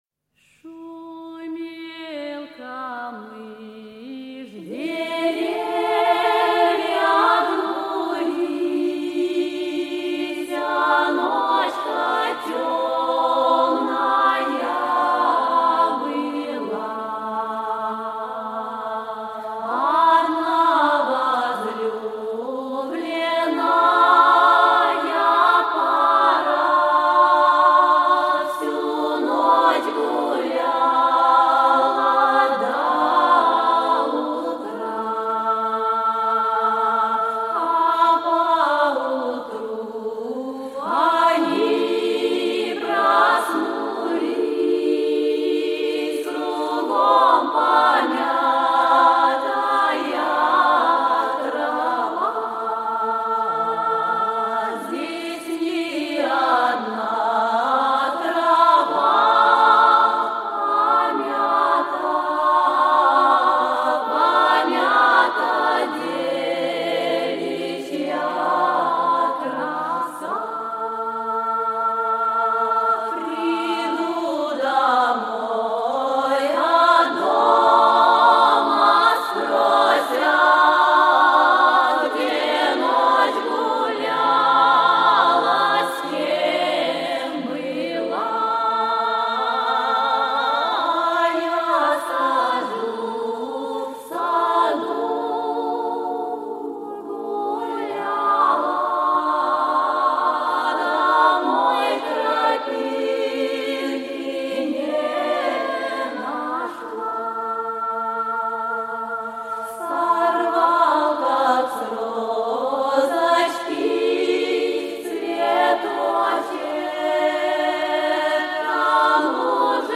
Русские народные песни
застольные песни